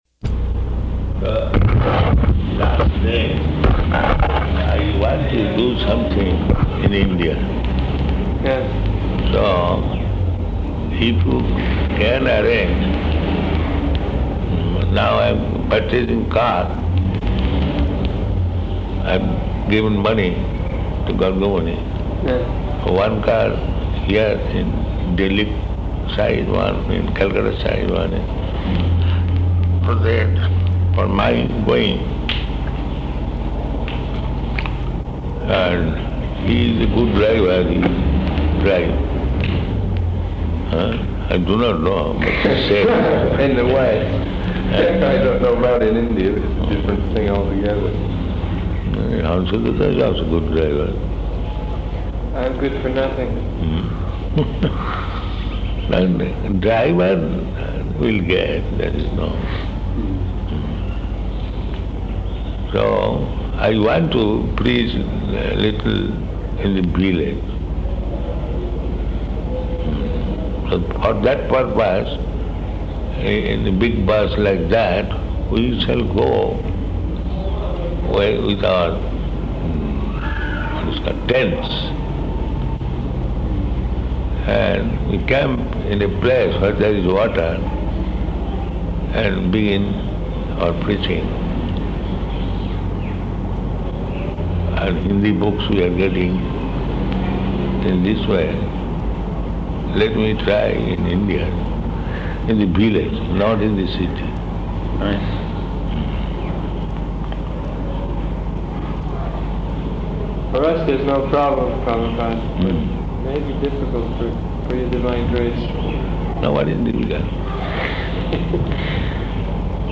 Room Conversation
Room Conversation --:-- --:-- Type: Conversation Dated: October 31st 1976 Location: Vṛndāvana Audio file: 761031R1.VRN.mp3 Prabhupāda: ...I want to do something in India.